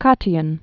(kŏtē-ən)